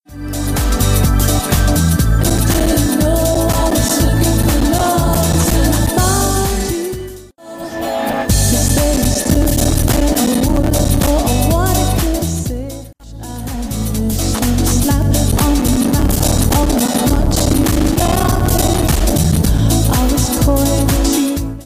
Allerdings habe ich nun ein anderes Problem: Wenn mAirList ca. 30-40 Minuten läuft, dann passiert auf einmal das, was man in der mp3-Datei hört: Die Audioausgabe wird, zuerst nur für ein paar Sekunden später dauerhaft verzerrt und langsamer.
Vielleicht noch wichtig: Ich habe zwei Player Die Verzerrungen beschränken sich aber immer nur auf einen Player.
Störungen mAirList.mp3 (254 KB)
Also der Signalweg ist folgender: Player --> Output Soundkarte --> Rein Ins Mischpult --> Raus aus dem Mischpult --> In den Input der Soundkarte --> In den Encoder --> und da dann vom “Aircheck-Encoder” aufgenommen.